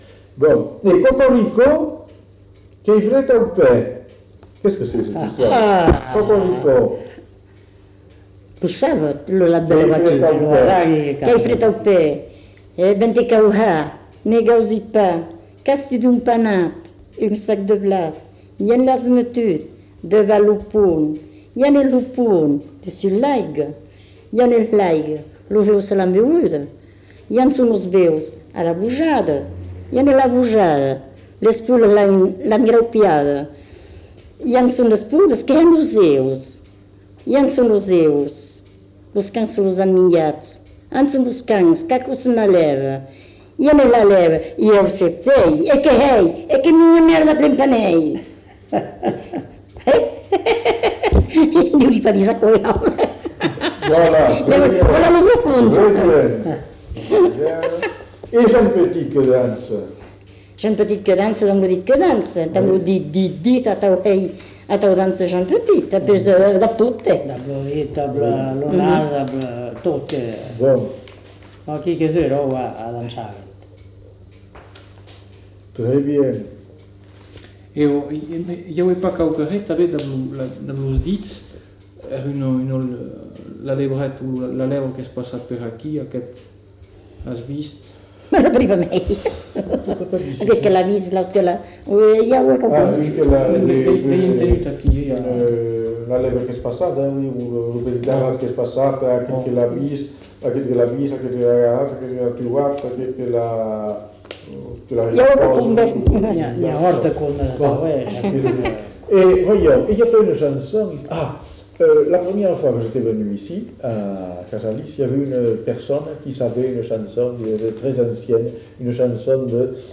Lieu : Cazalis
Genre : forme brève
Effectif : 1
Type de voix : voix de femme
Production du son : récité
Classification : mimologisme